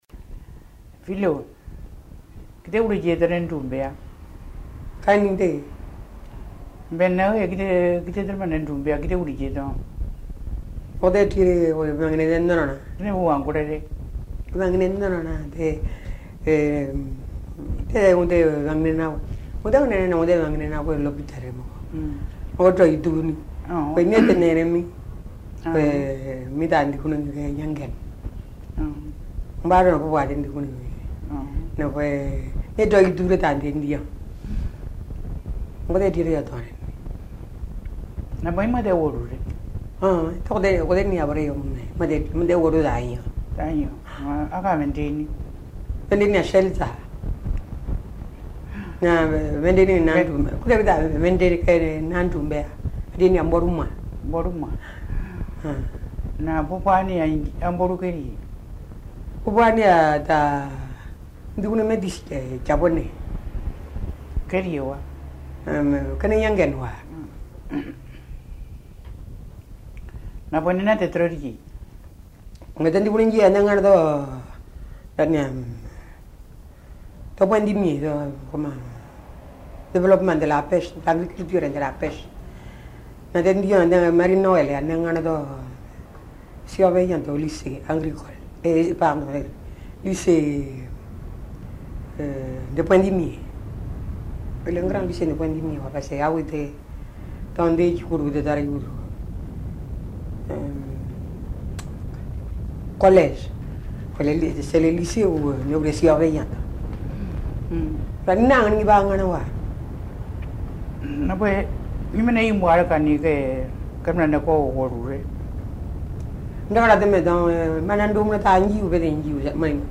Accueil > Dialogue > Dialogue > Drubéa